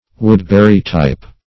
Woodbury-type \Wood"bur*y-type`\, n. [After the name of the